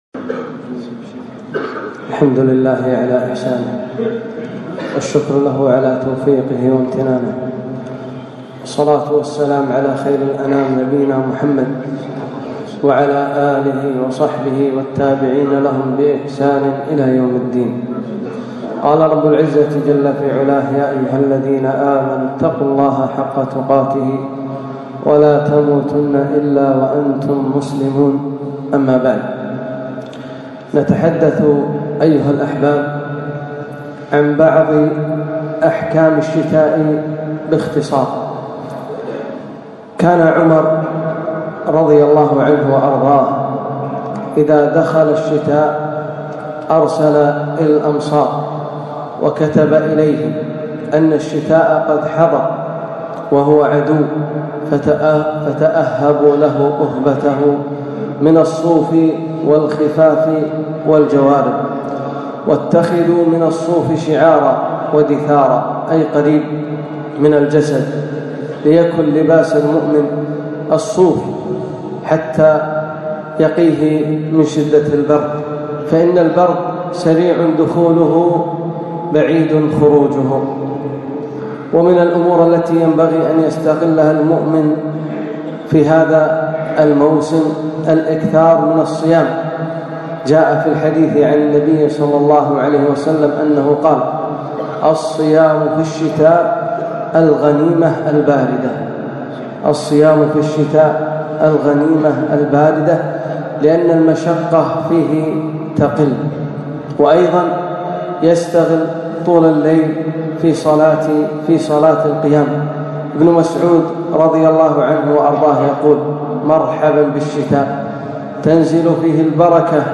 خطبة - أحكام الشتاء